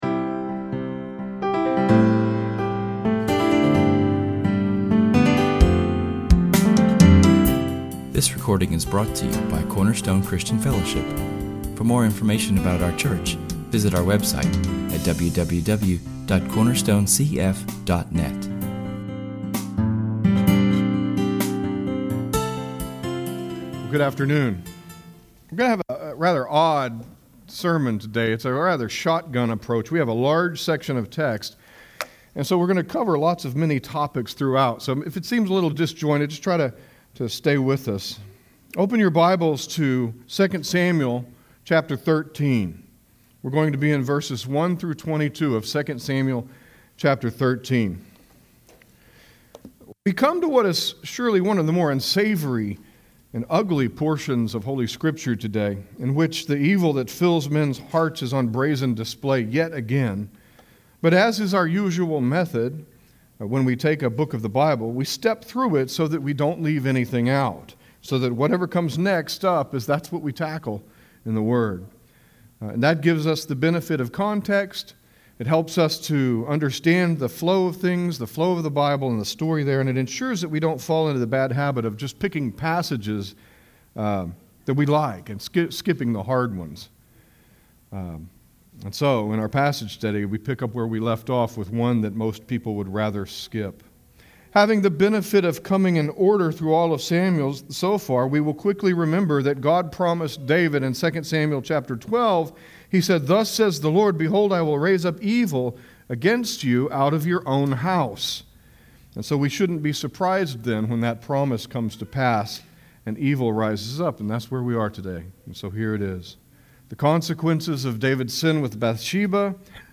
Our message is entitled Amnon: A Monster in David’s House.